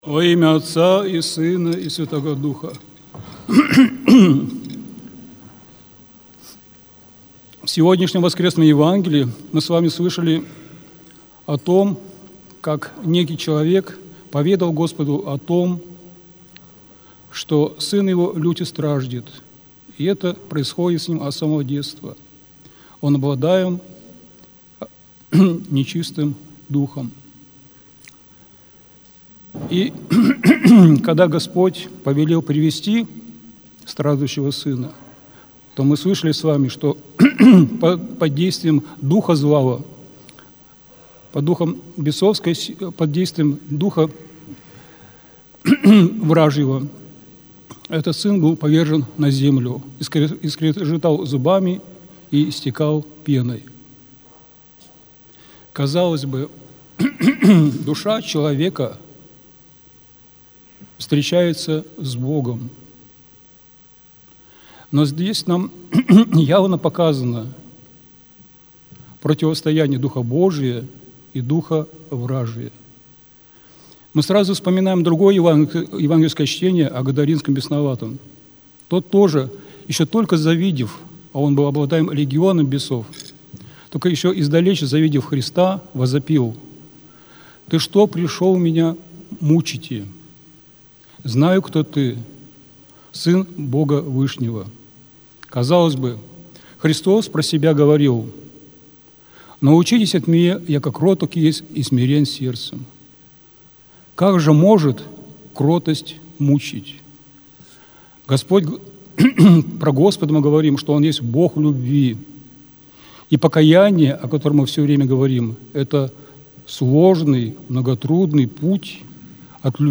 Аудиозапись проповеди
Храм Великомученика и Победоносца Георгия в Ендове